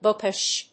音節book･ish発音記号・読み方bʊ́kɪʃ
• / ‐kɪʃ(米国英語)
• / ˈbʊk.ɪʃ(英国英語)